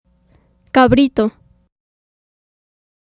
Cabrito (Spanish: [kaˈβɾito]